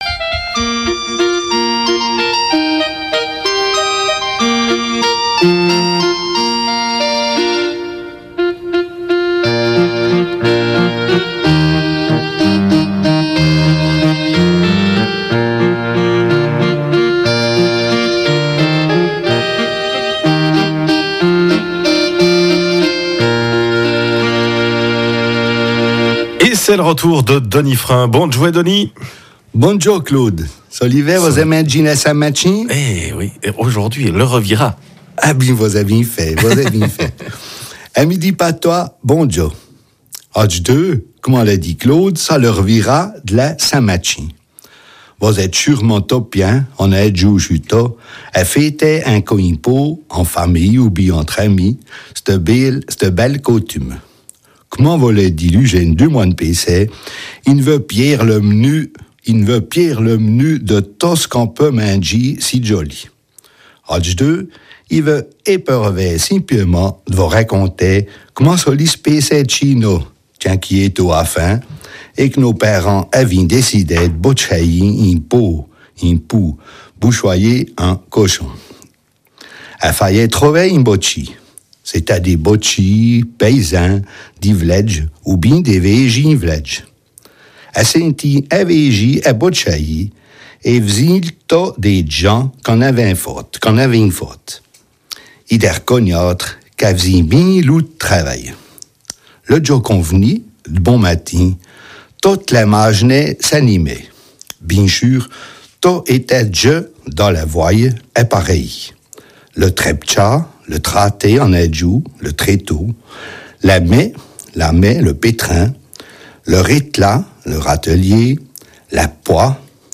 RFJ 16 novembre 2014 Radio Fréquence Jura RFJ Rubrique en patois du 16 novembre 2014 Auteur